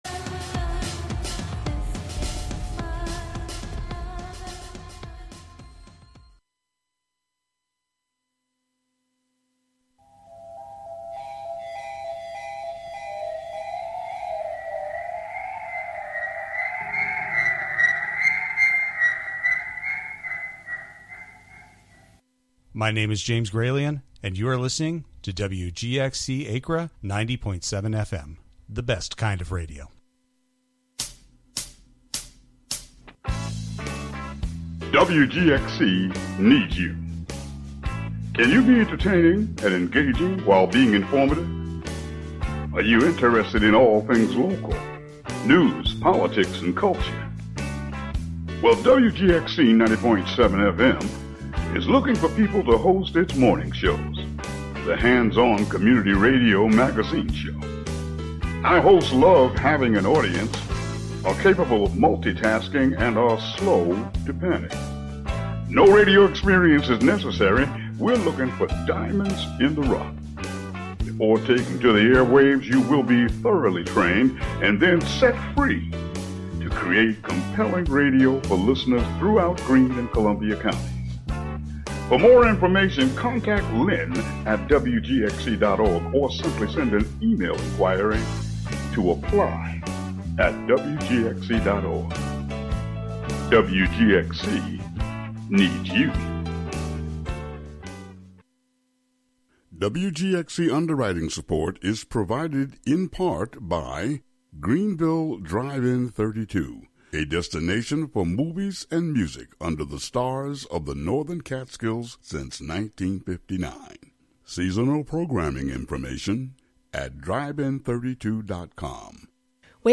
Turn the dial to 90.7-FM at 5:30 p,m. for another DJ set by the Cool Katts!